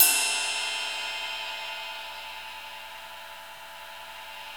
CYM XRIDE 1B.wav